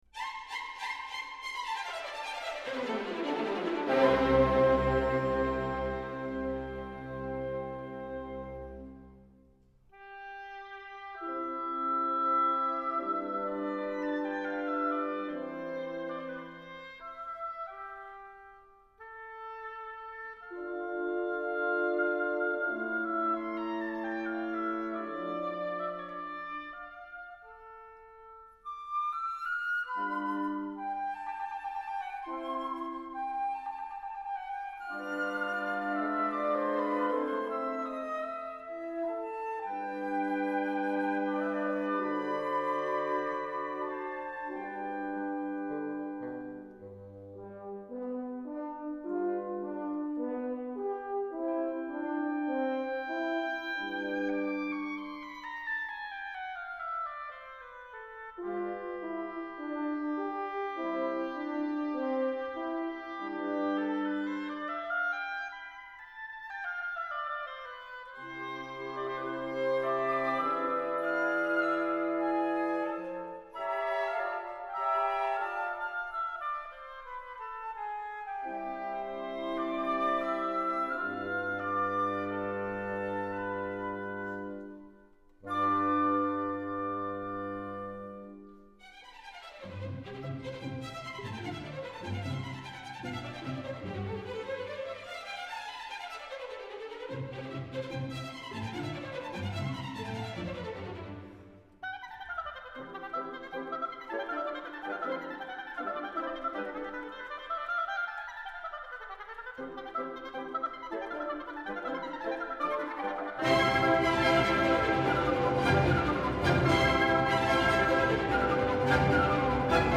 Incontro con Michele Mariotti